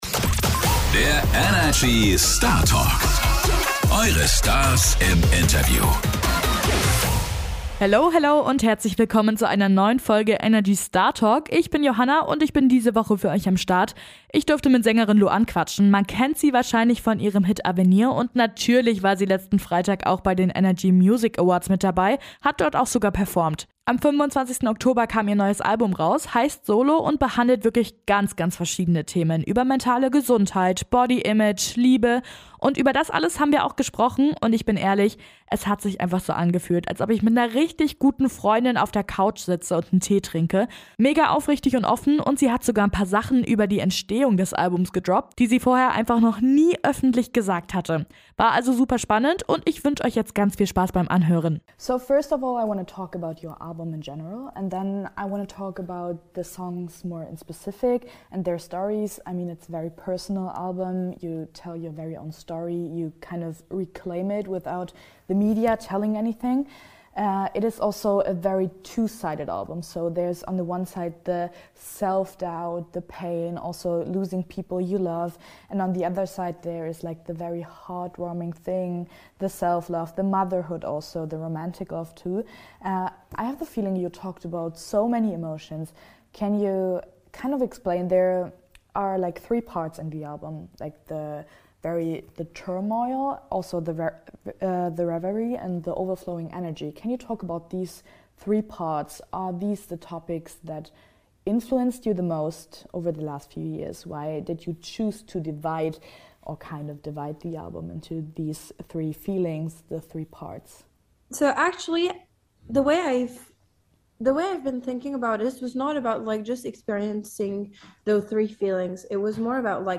In dieser Folge sprechen wir mit der französischen Sängerin Louane, die vor Kurzem ihr neues Album "solo" rausgebracht hat.